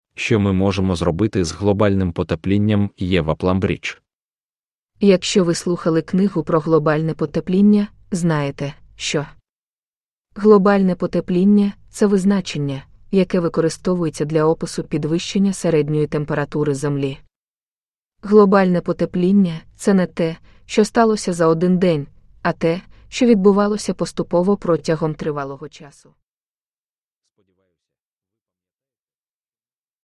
What Can We Do Against Global Warming – Ljudbok